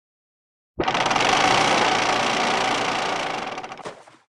anchorup.ogg